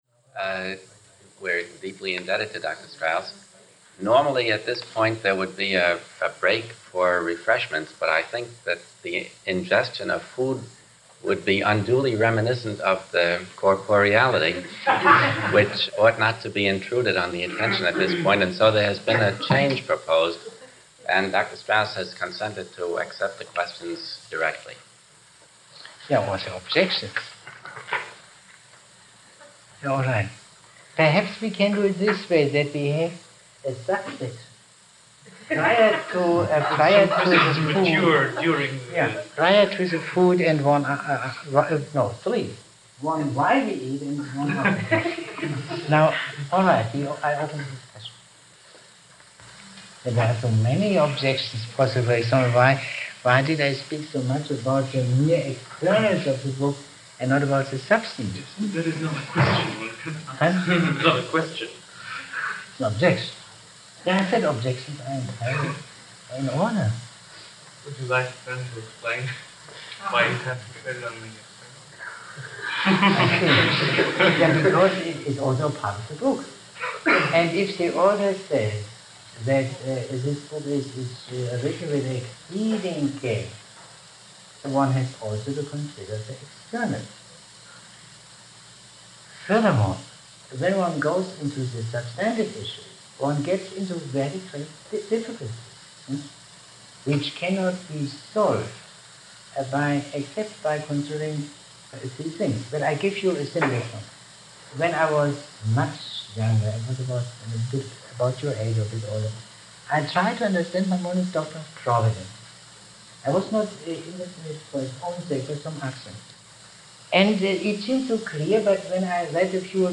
Lectures on Maimonides given by Leo Strauss at the Hillel Foundation at the University of Chicago on February 7 & 14, 1960, and a discussion session following the February 14 lecture.